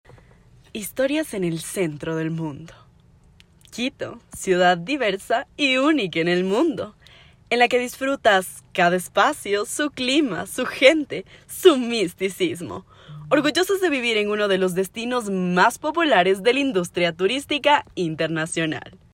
Voz femenina joven.
spanisch Südamerika
Sprechprobe: Industrie (Muttersprache):
Young Woman voice.